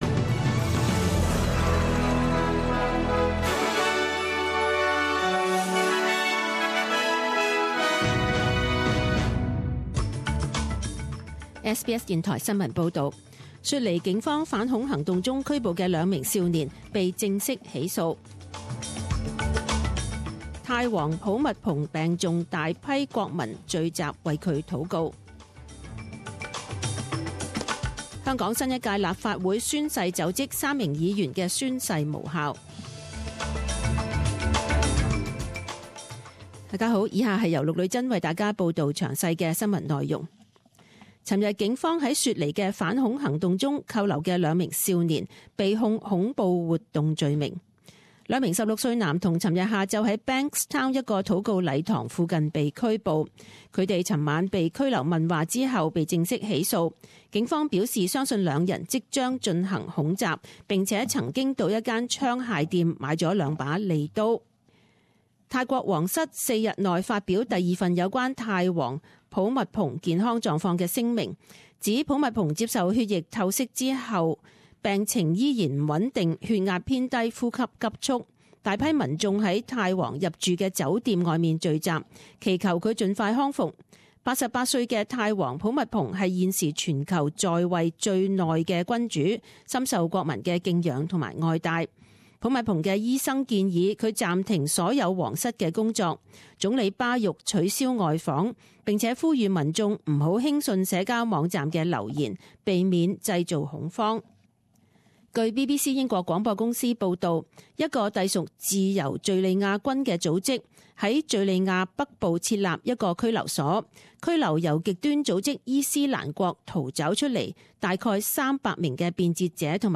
Detailed morning news bulletin